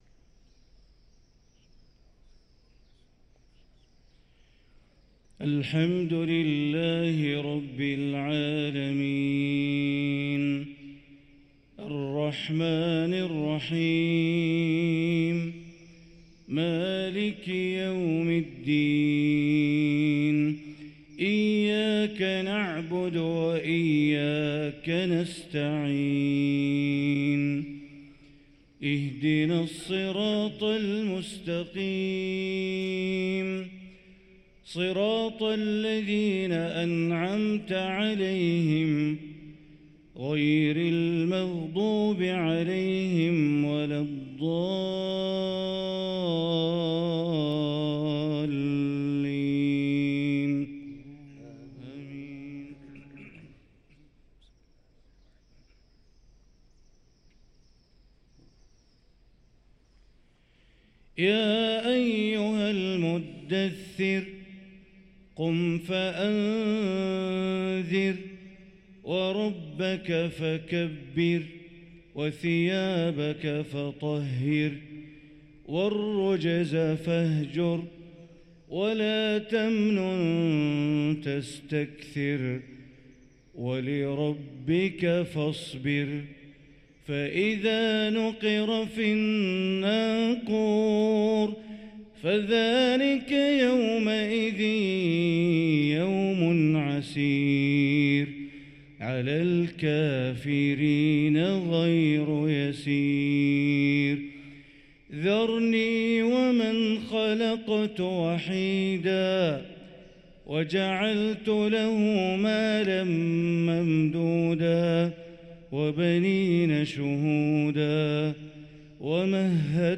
صلاة الفجر للقارئ بندر بليلة 26 جمادي الآخر 1445 هـ